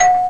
ding_s_dong
ding house ringtone sound effect free sound royalty free Sound Effects